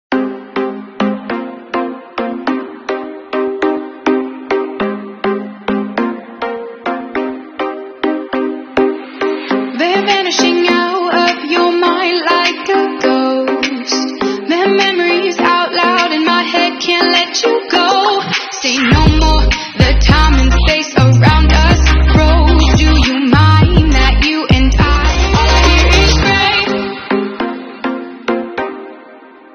各地游客篝火狂欢、劲舞嗨歌；